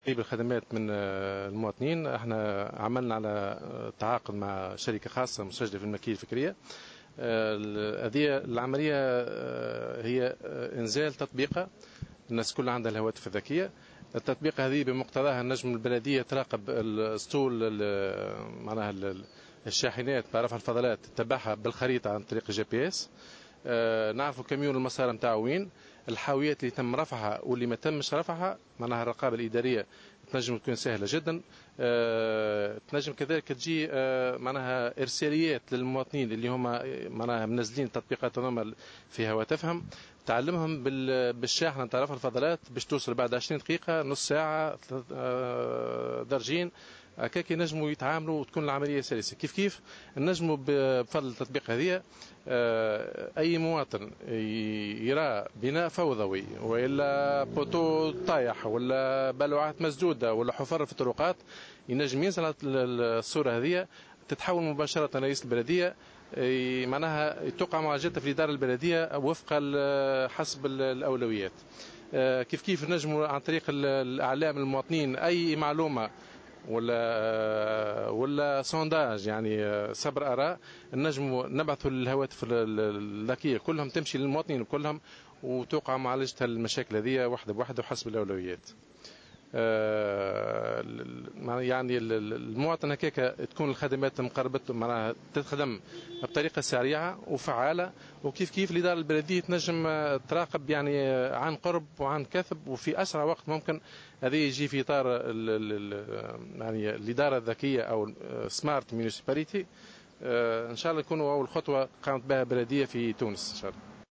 رئيس النيابة الخصوصية بقرمبالية